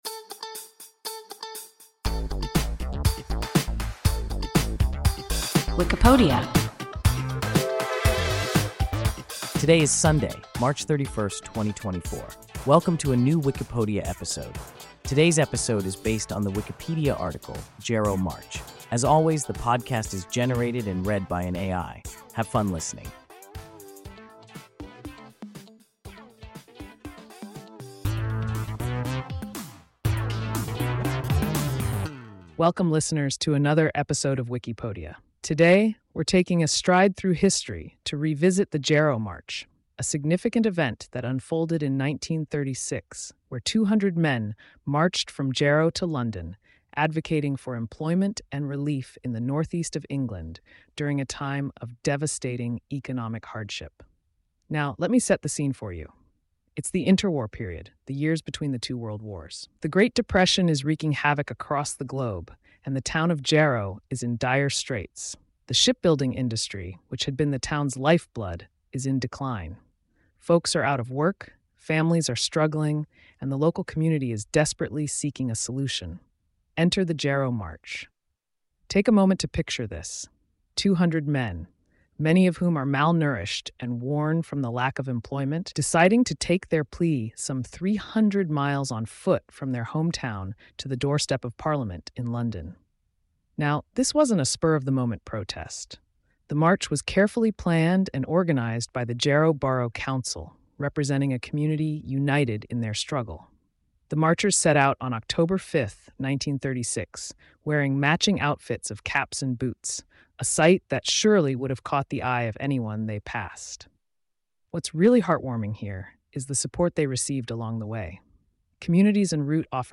Jarrow March – WIKIPODIA – ein KI Podcast